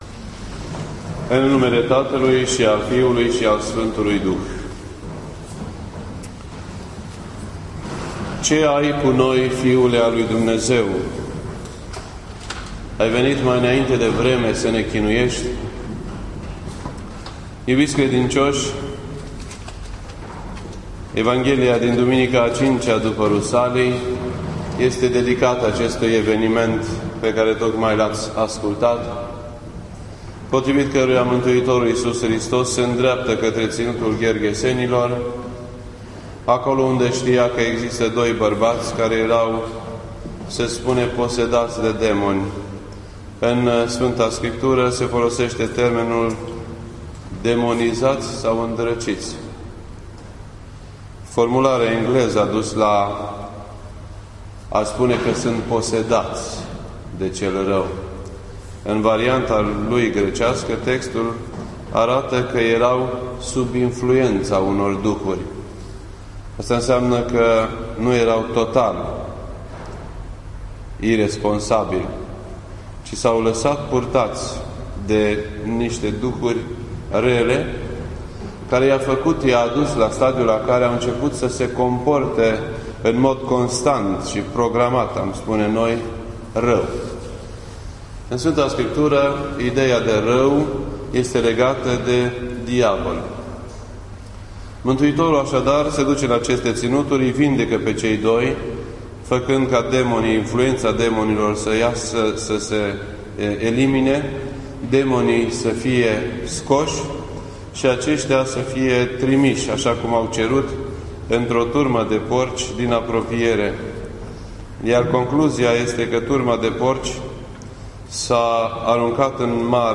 This entry was posted on Sunday, July 8th, 2012 at 7:58 PM and is filed under Predici ortodoxe in format audio.